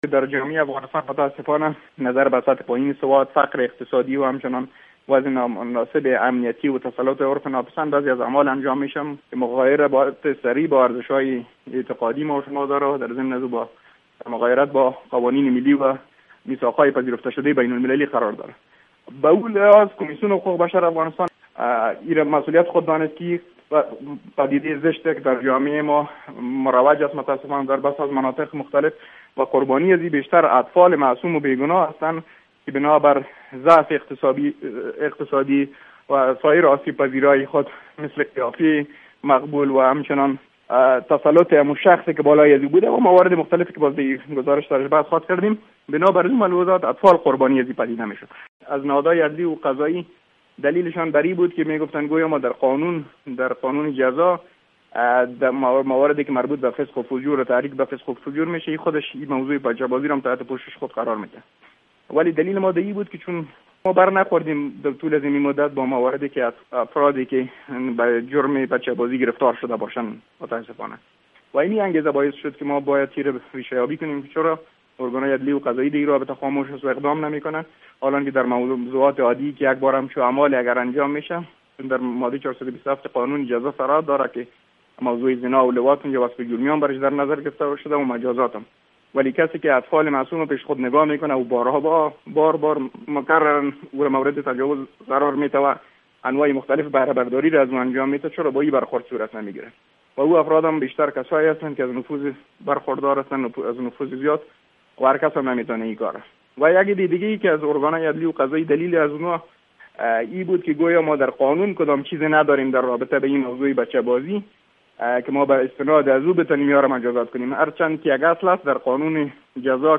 گزارش های ویژه